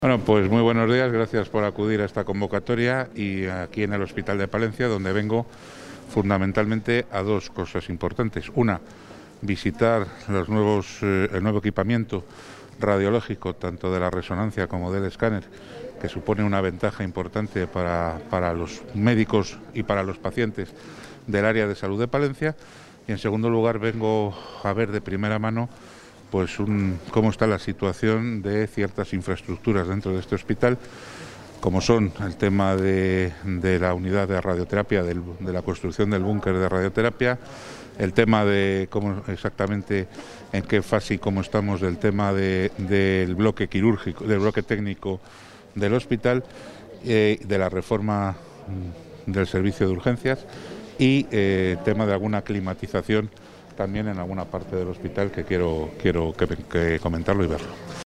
Material audiovisual de la visita del consejero de Sanidad al Hospital Río Carrión de Palencia.
Intervención del consejero.